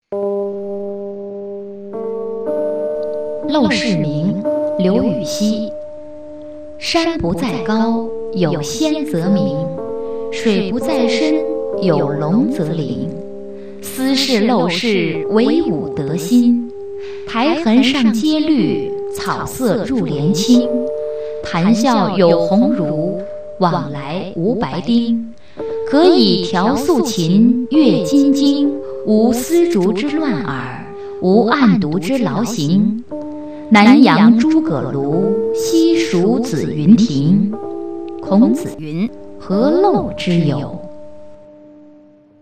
刘禹锡《陋室铭》原文与译文（含配乐朗读）